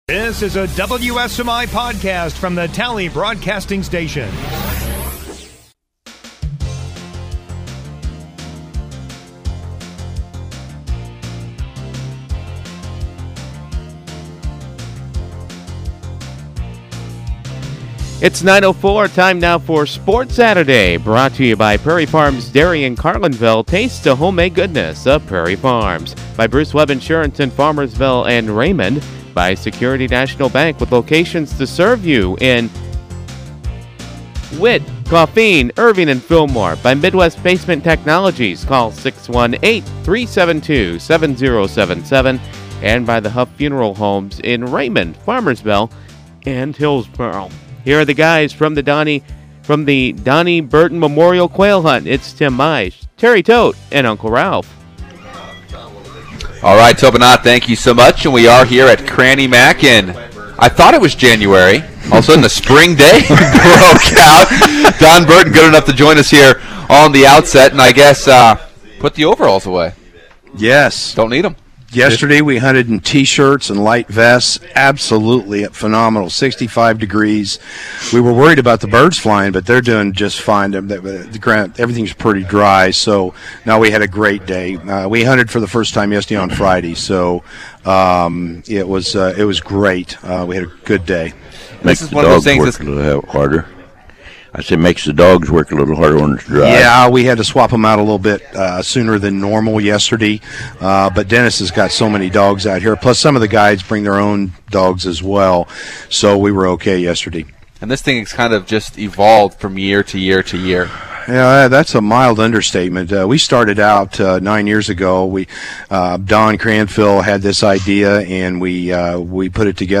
at Cranny Mac